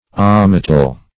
ommateal - definition of ommateal - synonyms, pronunciation, spelling from Free Dictionary Search Result for " ommateal" : The Collaborative International Dictionary of English v.0.48: Ommateal \Om`ma*te"al\, a. (Zool.) Of or pertaining to an ommateum.